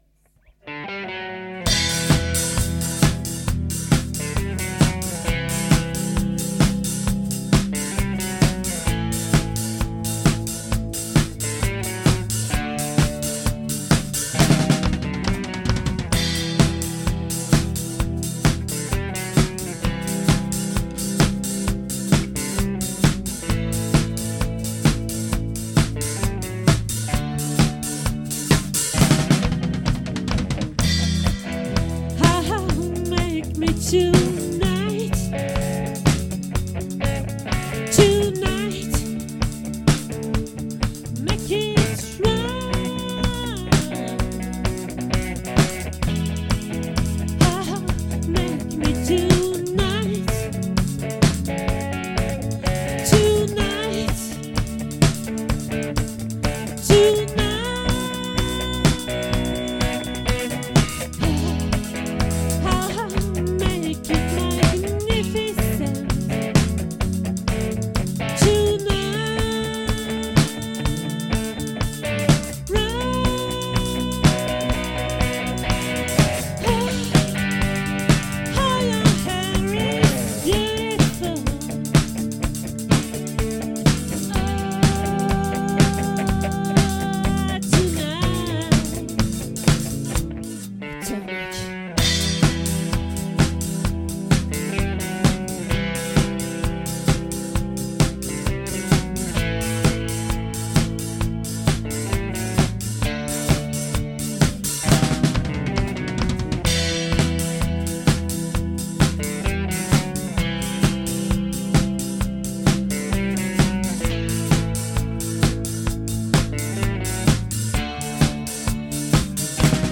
🏠 Accueil Repetitions Records_2023_06_14_OLVRE